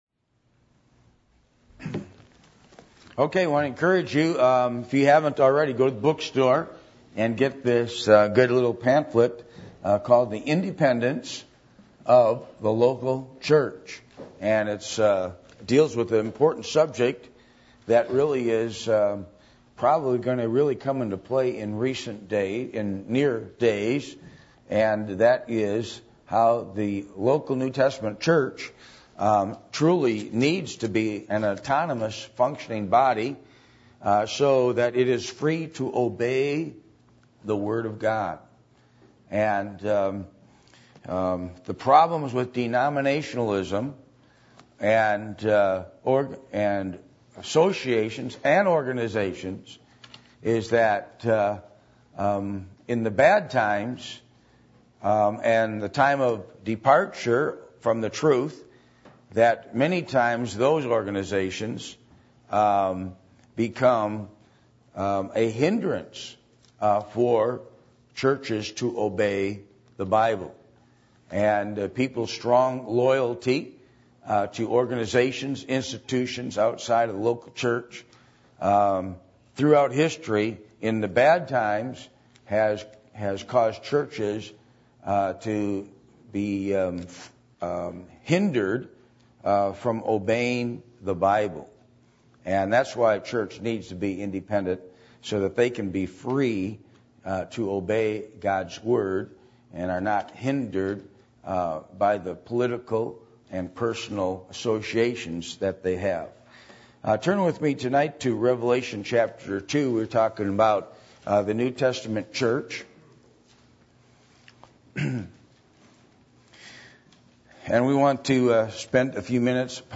Passage: Revelation 2:1-7 Service Type: Midweek Meeting